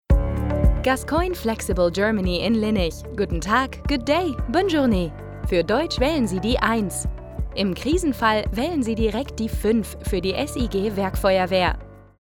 Natural, Cool, Playful, Versatile, Friendly
Telephony